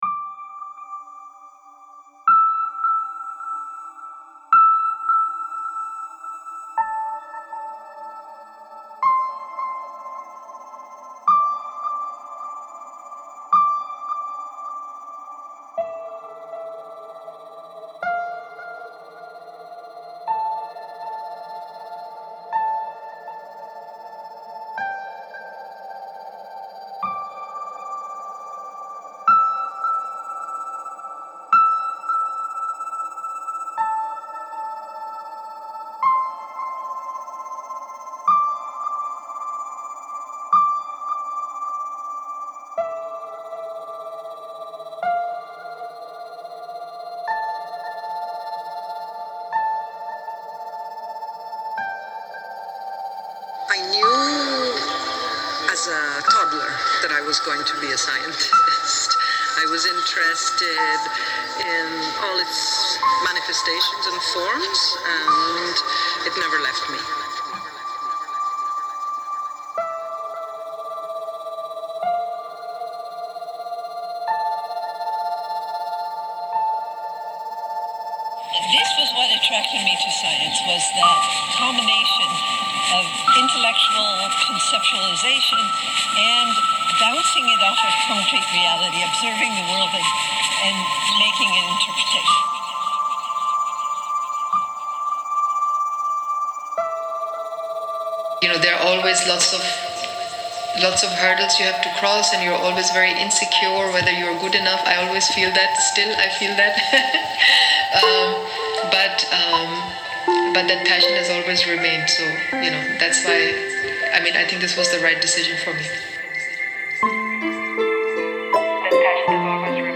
SOUND DESIGN
I thought it would be emblematic to ask these scientists to ‘declare their passion’ for science through a short voice message in their native languages.
The architecture of the exhibition spaces, their minimalism and visual cleanness inspired and guided me in composing and creating clean and minimal soundscapes.
STEM-PASSION_SOUND-DESIGN-for-WEB.mp3